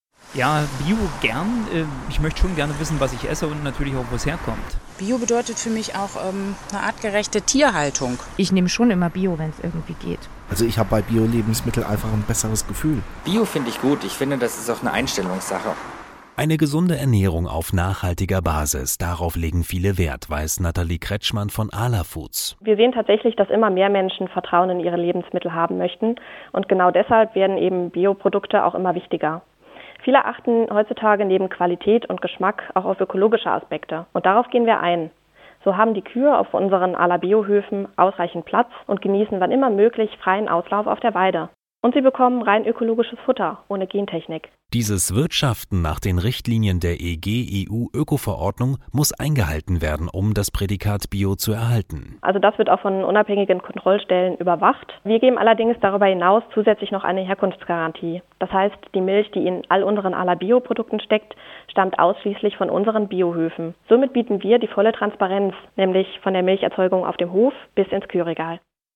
Beitrag mit Gewinnspiel